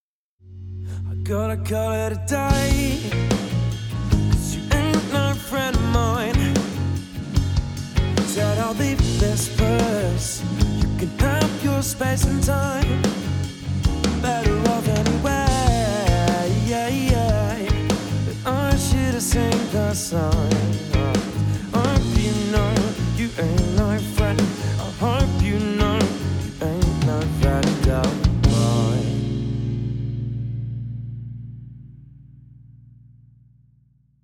No UV EQ applied to audio
Mix Dry.wav